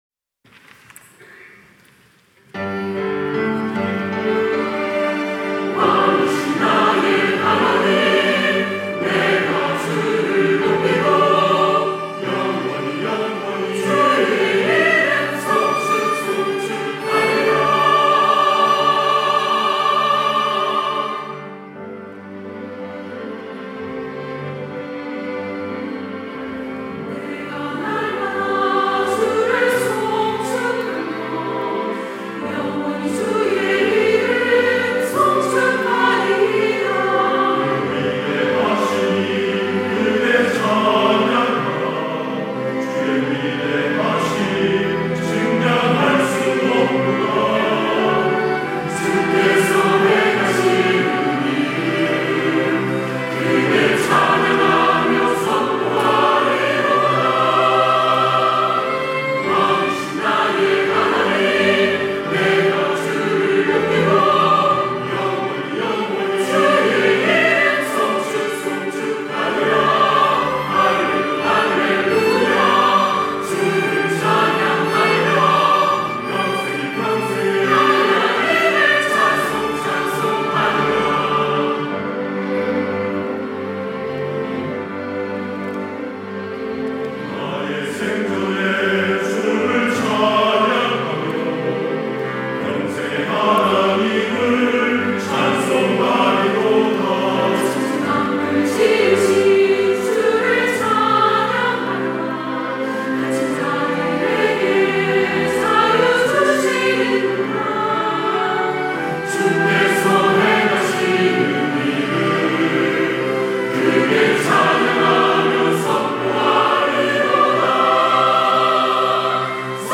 할렐루야(주일2부) - 왕이신 나의 하나님
찬양대